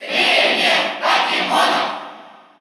Category: Crowd cheers (SSBU) You cannot overwrite this file.
Pokémon_Trainer_Cheer_Russian_SSBU.ogg